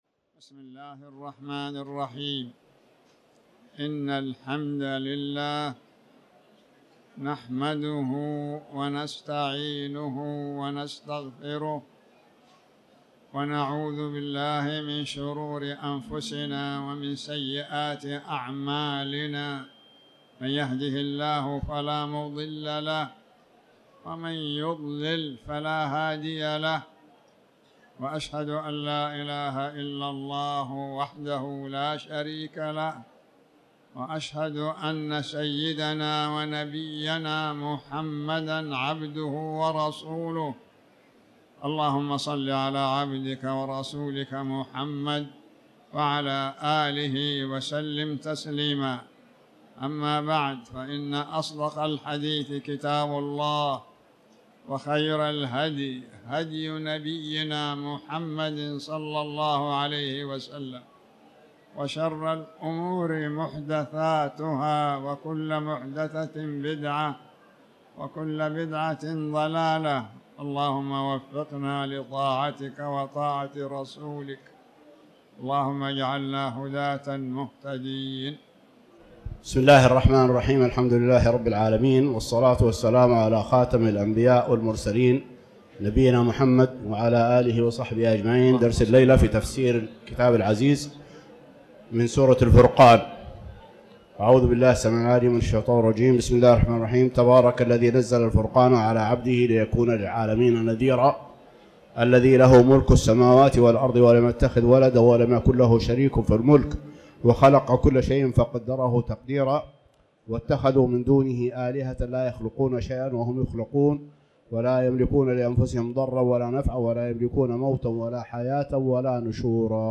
تاريخ النشر ١٥ جمادى الآخرة ١٤٤٠ هـ المكان: المسجد الحرام الشيخ